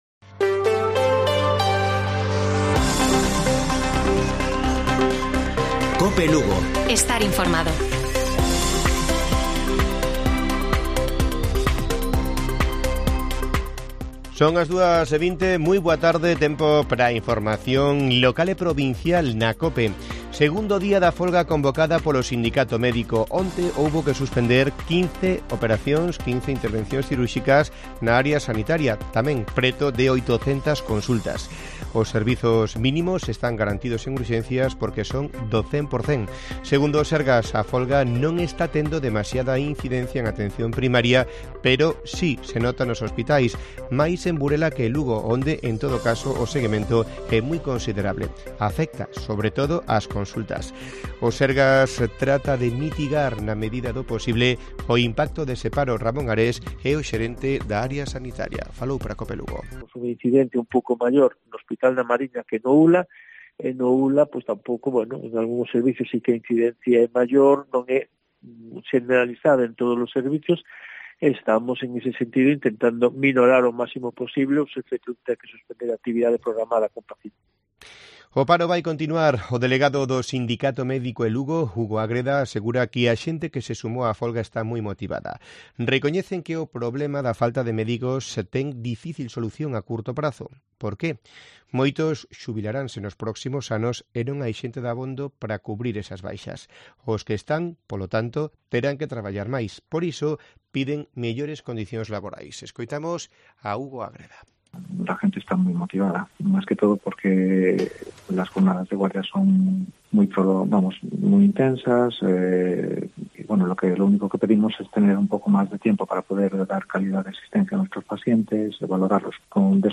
Informativo Mediodía de Cope Lugo. 12 DE ABRIL. 14:20 horas